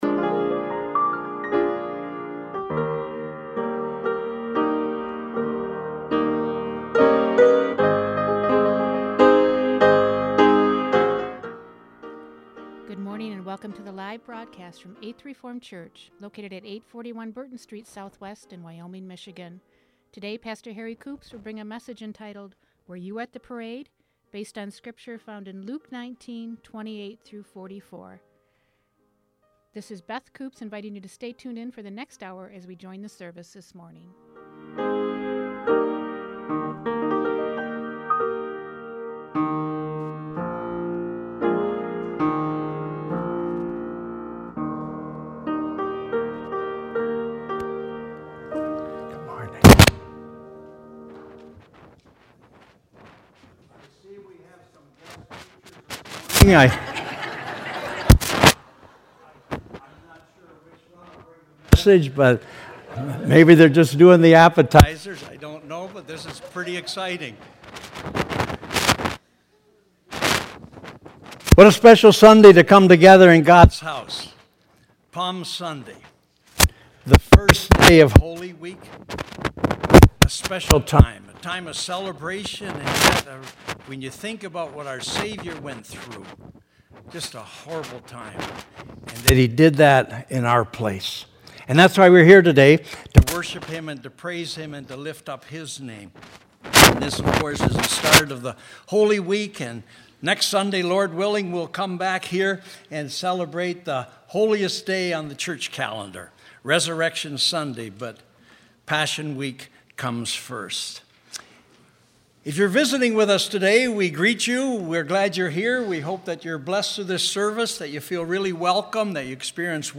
Worship Services | Eighth Reformed Church